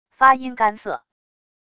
发音干涩wav音频